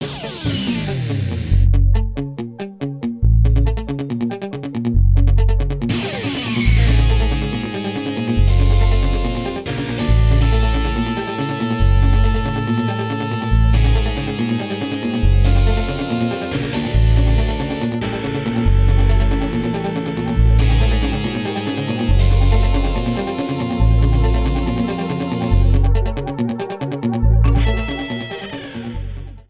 applied fade-out to last two seconds